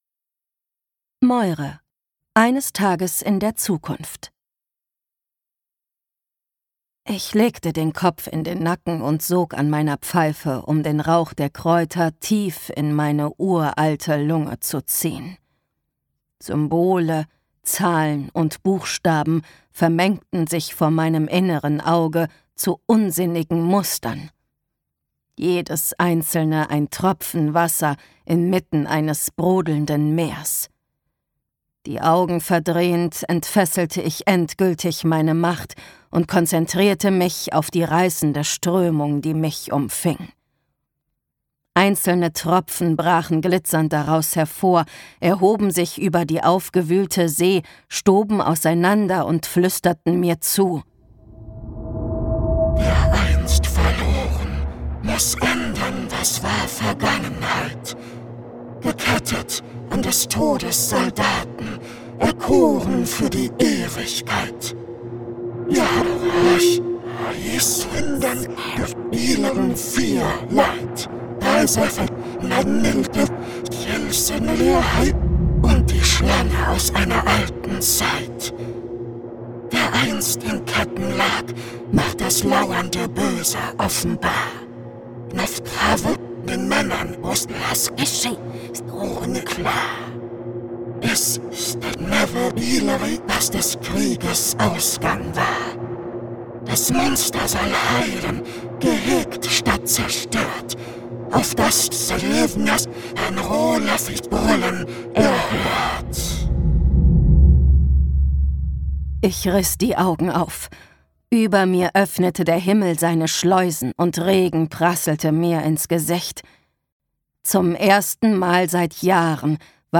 Das perfekte Dark-Romantasy-Hörbuch mit all unseren Lieblingstropes:
Gekürzt Autorisierte, d.h. von Autor:innen und / oder Verlagen freigegebene, bearbeitete Fassung.